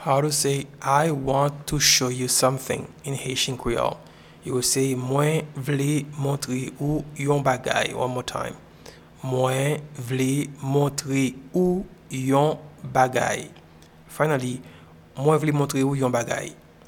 Pronunciation and Transcript:
I-want-to-show-you-something-in-Haitian-Creole-Mwen-vle-montre-ou-yon-bagay.mp3